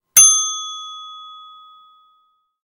better ding.